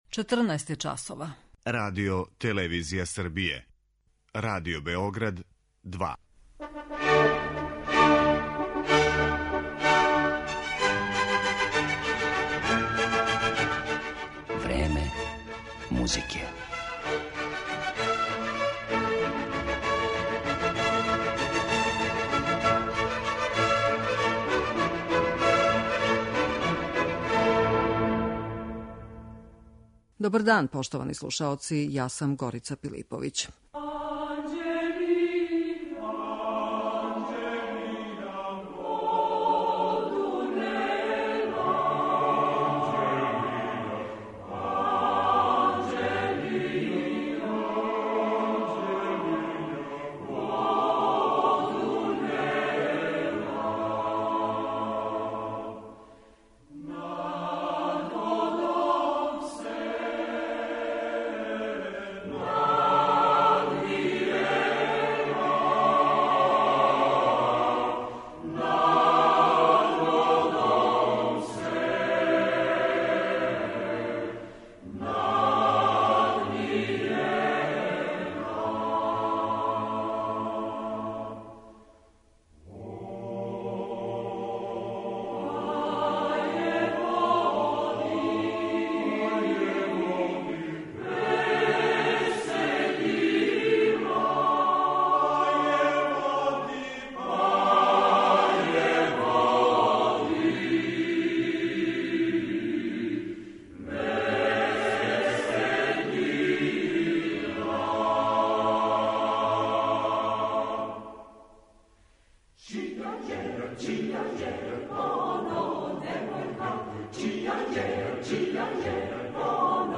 Српска народна песма и концепције националне уметности – била је оквирна тема троје наших музиколога који су говорили на једном од форума Музиколошког института САНУ.